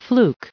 added pronounciation and merriam webster audio
352_fluke.ogg